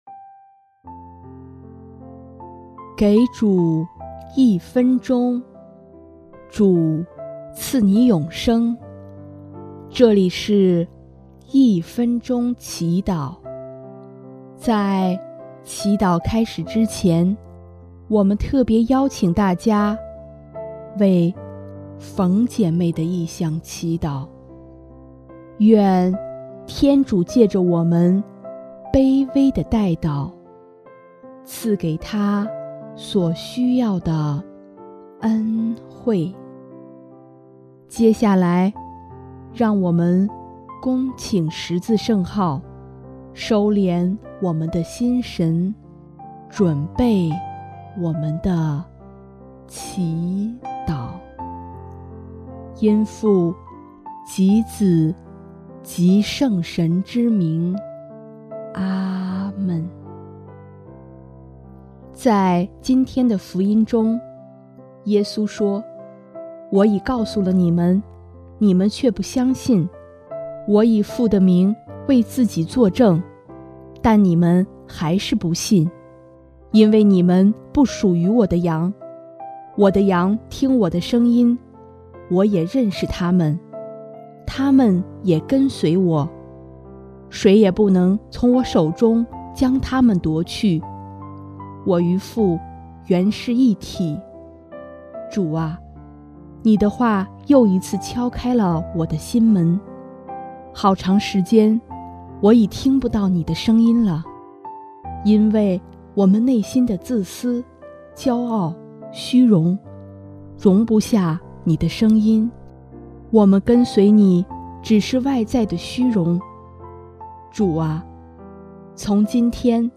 音乐： 第二届华语圣歌大赛参赛歌曲《牧羊人》